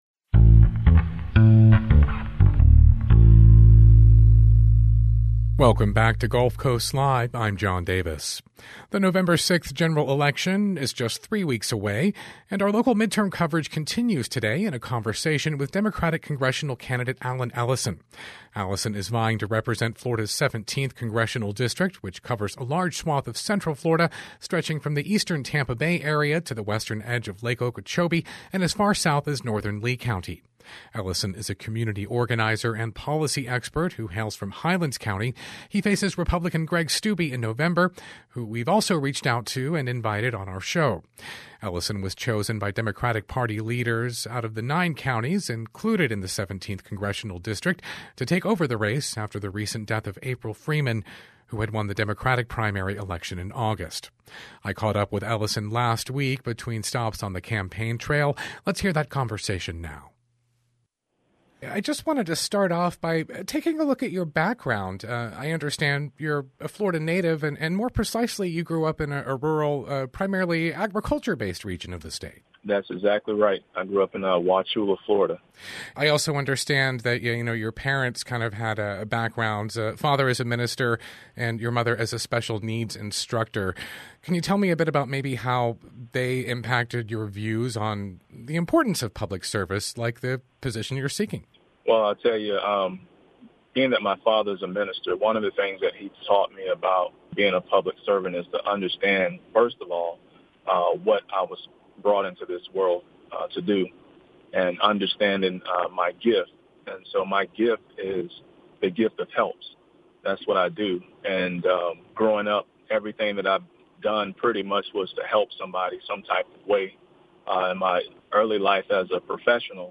A Conversation with Candidate